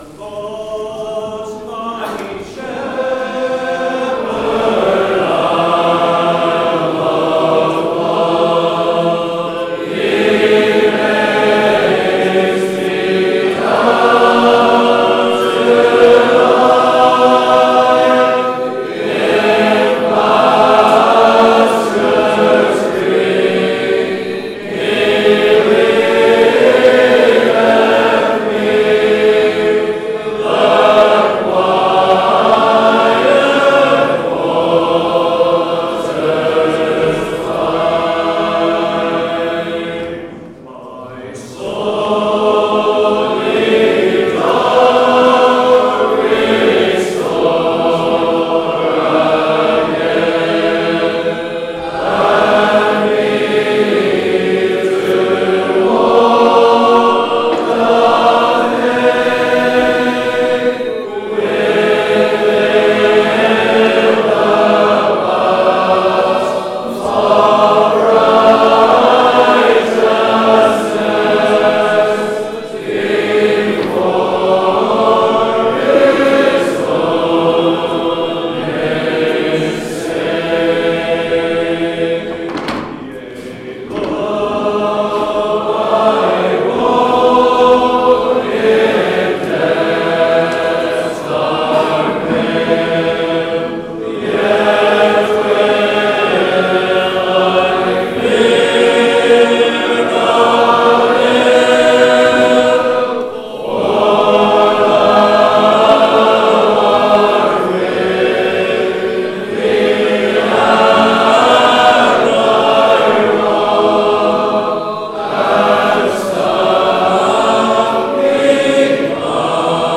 Hymn Singing from the Easter Monday meetings of the Easter Conference weekend.
2026-Easter-Hymn-Singing-Part-2.mp3